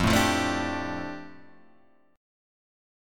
F 6th Add 9th